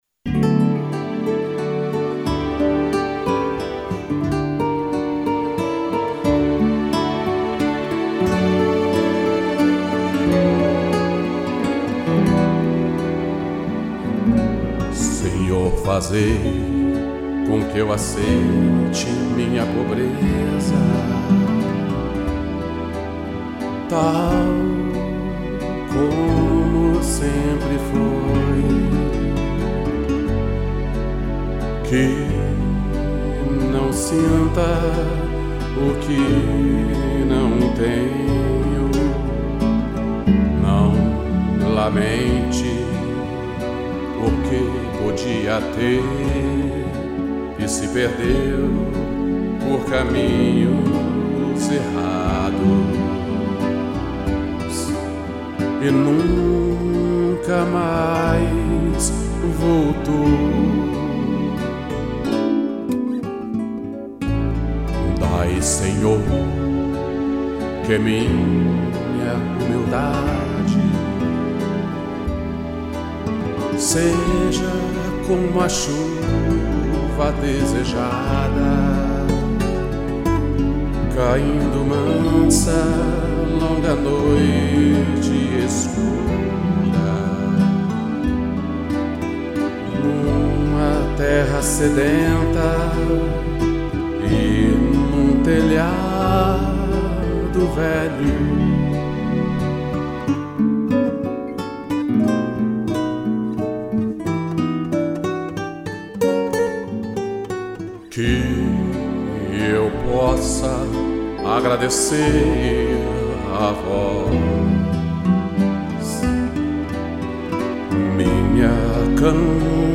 interpretação e violão